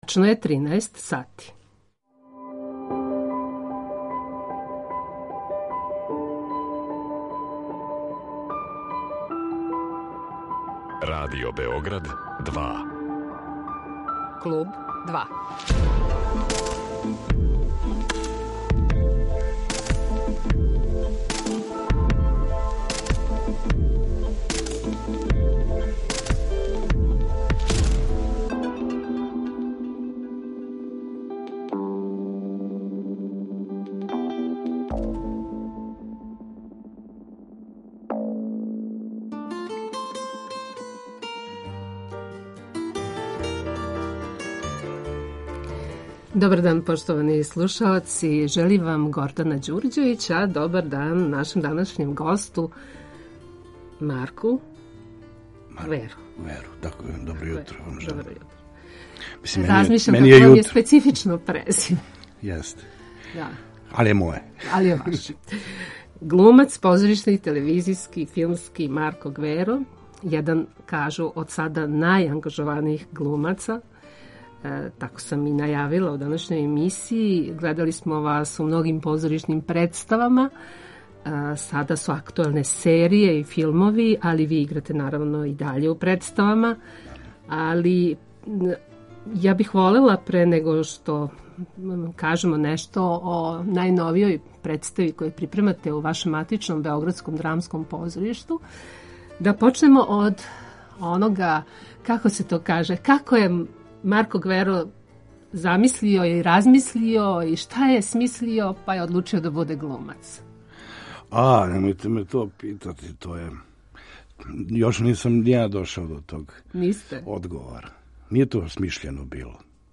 Гост Клуба 2 је позоришни, телевизијски и филмски глумац Марко Гверо.